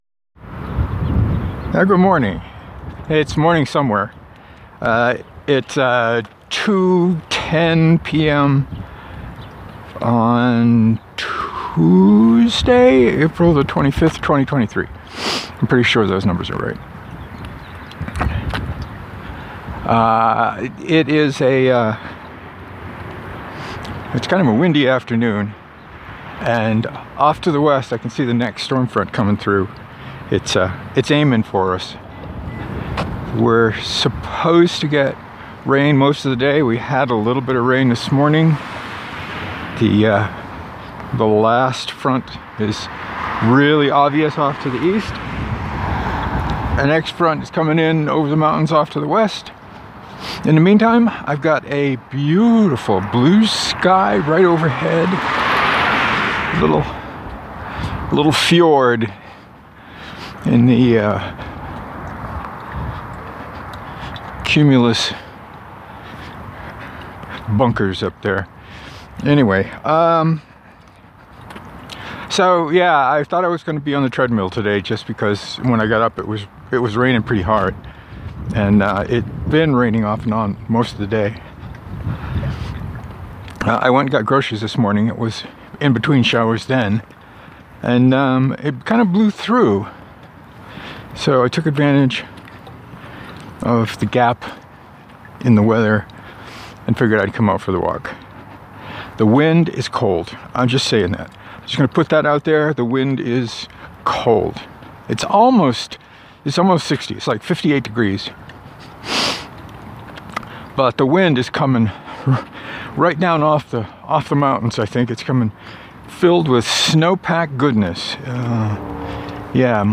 I ducked out between the fronts to get my walk in. A cold wind kept me moving. I talked a lot about writing, what I count as writing, and struggling with my first sour dough loaf from the new starter.